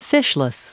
Prononciation du mot fishless en anglais (fichier audio)
Prononciation du mot : fishless